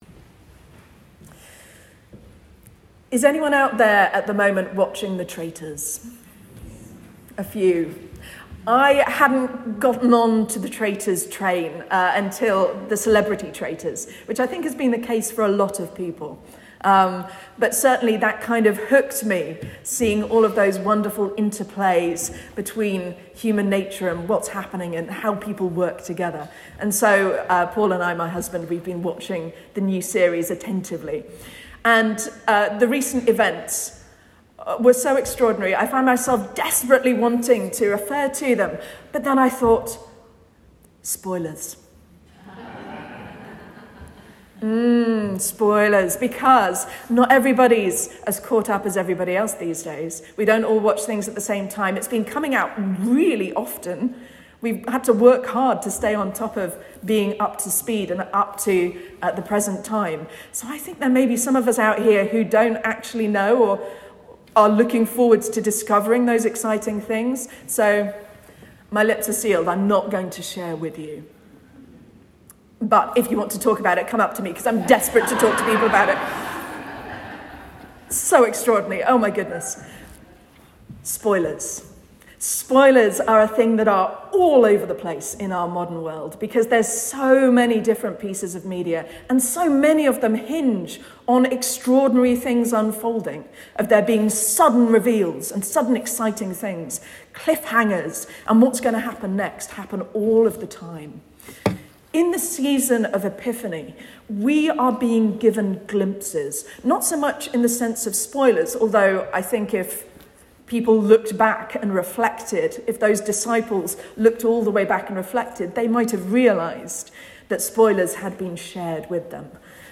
Sermon and Readings for Sunday 11th January 2026